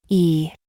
E_E-eve-phoneme-name-AI.mp3